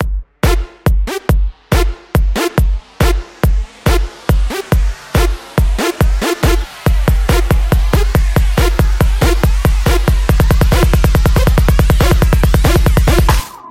标签： 140 bpm Trap Loops Drum Loops 2.31 MB wav Key : Unknown
声道立体声